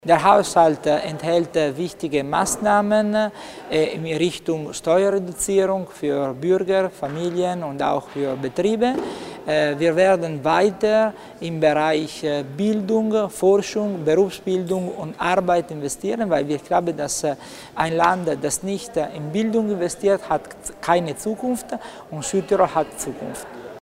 Landeshauptmannstellvertreter Tommasini zu den Details des Haushalts